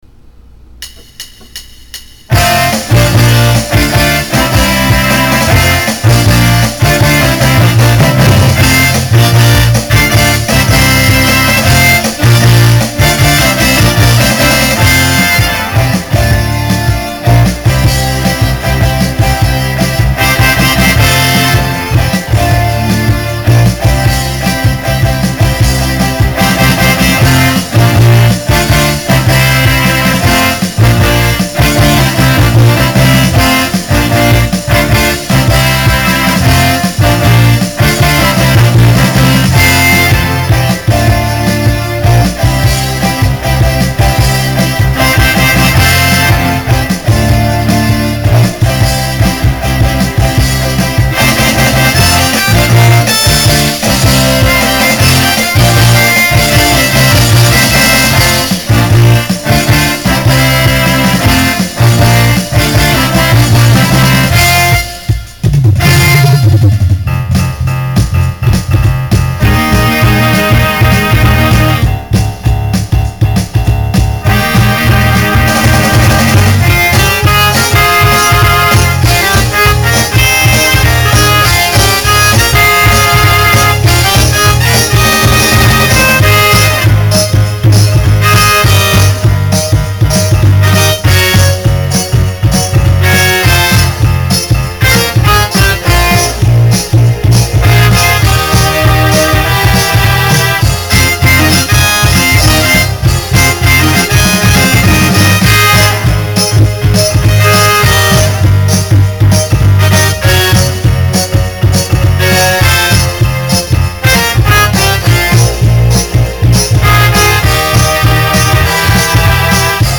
SSA/TTB
Voicing Mixed Instrumental combo Genre Rock